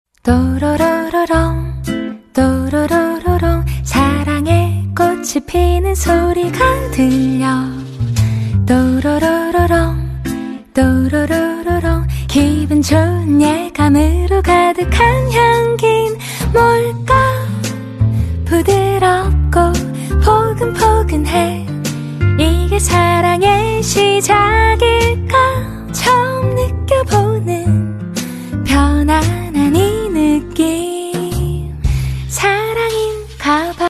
POV: Plush Fruit Cutting ASMR sound effects free download